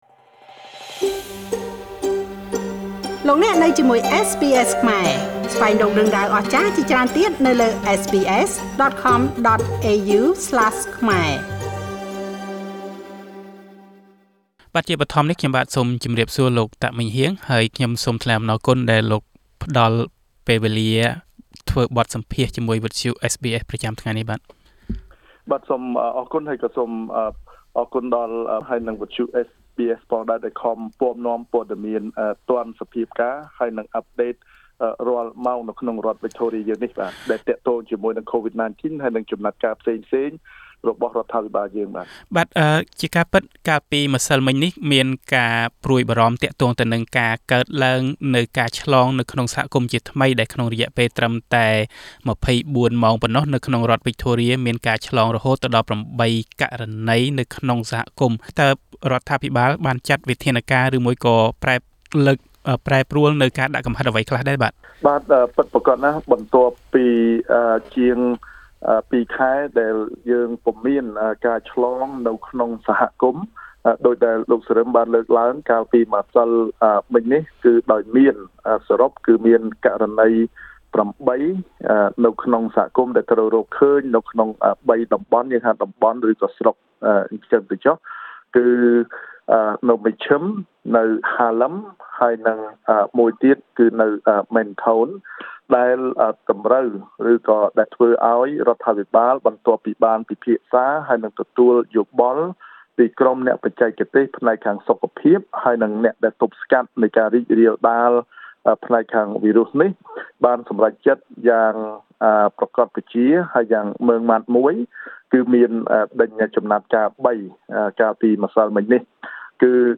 បទសម្ភាសន៍ពីវិធានការរដ្ឋាភិបាលវិកថូរៀក្នុងការទប់ស្កាត់ការឆ្លងក្នុងសហគមន៍
រដ្ឋវិកថូរៀចាប់ផ្តើមផ្ទុះមានការឆ្លងកូវីដ 19តាមសហគមន៍ជាថ្មីបន្ទាប់ពីរដ្ឋនេះគ្មានការឆ្លងក្នុងសហគមន៍រយៈពេលប្រមាណពីរខែកន្លងមកហើយ។ តើអាជ្ញាធរបានចាត់វិធានការអ្វីខ្លះដើម្បីទប់ស្កាត់ការនេះកុំឲ្យរាលដាលកាន់តែធំ? តទៅនេះយើងខ្ញុំនឹងនាំមកជូននូវបទសម្ភាសន៍ជាមួយលោក តាក ម៉េងហ៊ាង សមាជិកសភារដ្ឋវិកថូរៀដូចតទៅ ៖
Victorian MP Meng Heang Tak Source: Heang Tak Facebook Page